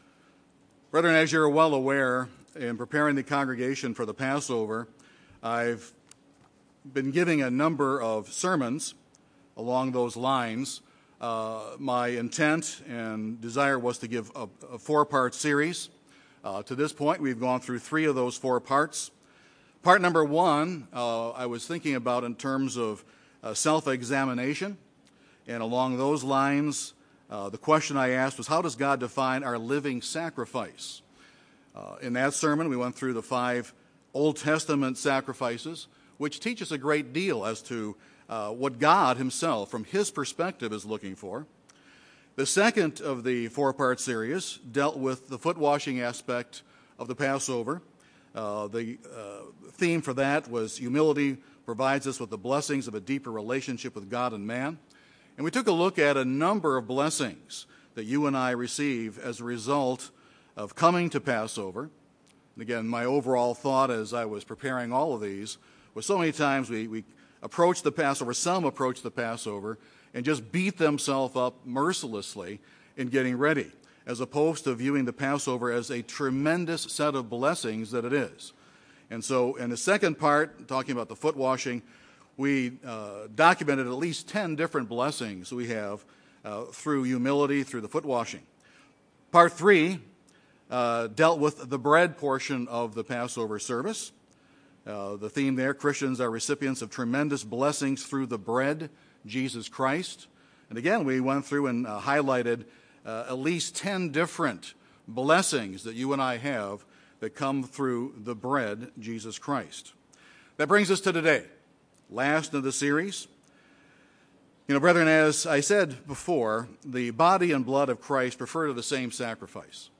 This is the last sermon in a four part series preparing us for the Passover.